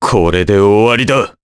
Crow-Vox_Skill4_jp.wav